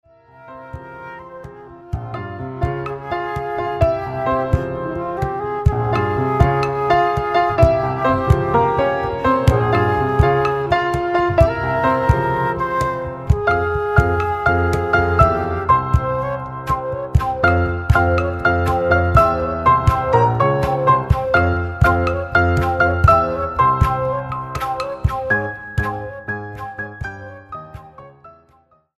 cello, voice, and percussion
transverse flute and percussion